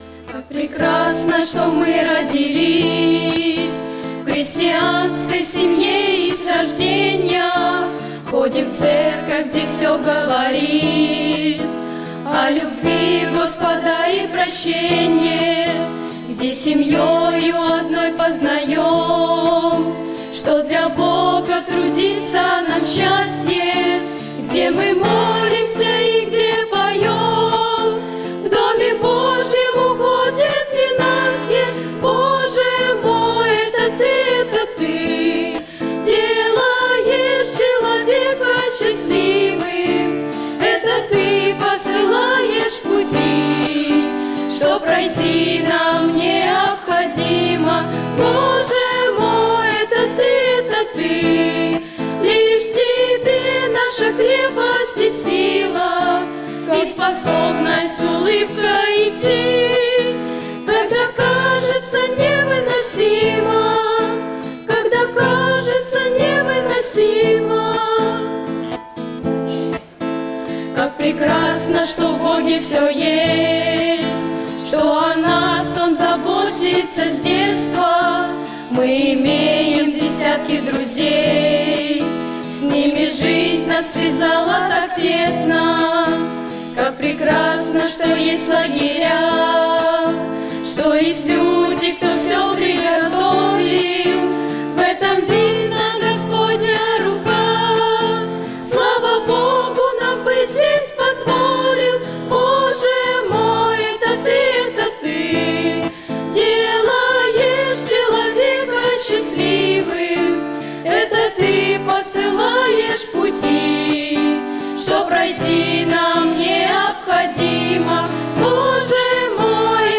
Богослужение 14.07.2019
Как прекрасно, что мы родились - Подростки (Пение)[